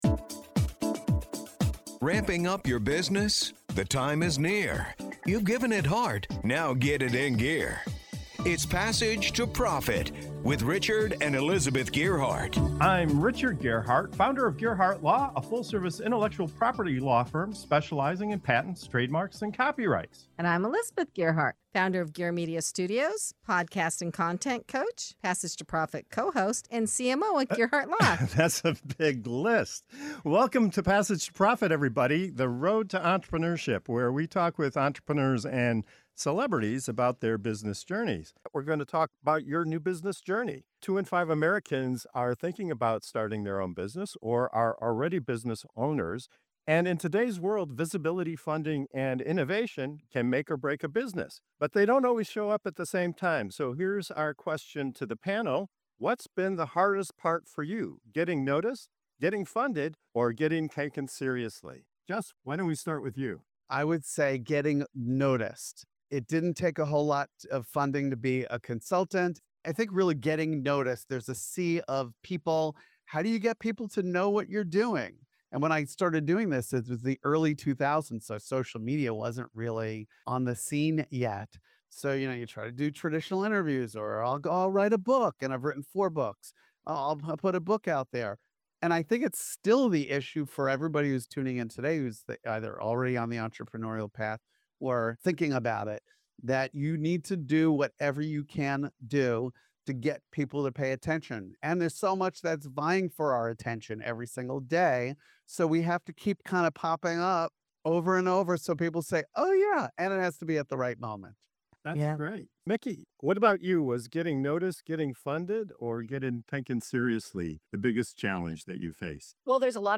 In this segment of "Your New Business Journey" on Passage to Profit Show, our panel of entrepreneurs dives into one of the biggest questions facing anyone starting a business today: What’s the hardest part—getting noticed, getting funded, or getting taken seriously?